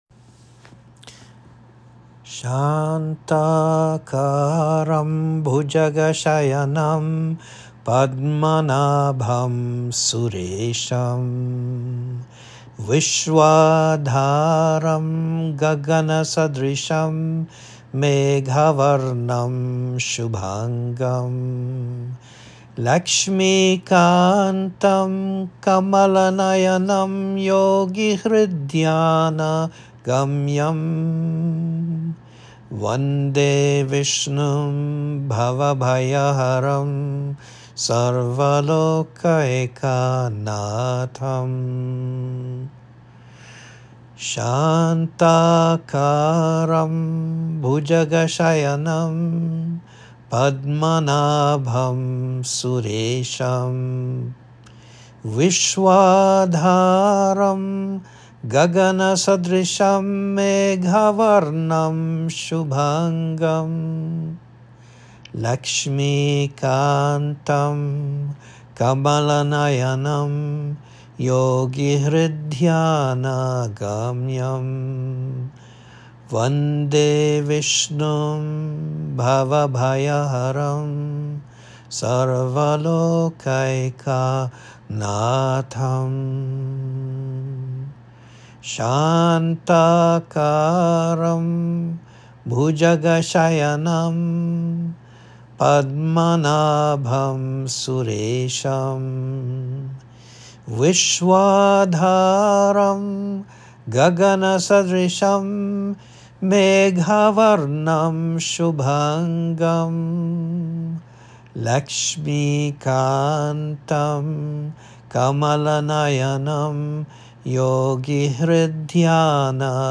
Pujas and Yagyas
Yagya uses subtle sound both on the verbal and mental levels.
vrp-VISHNU INVOCATION VYASA RISHI.m4a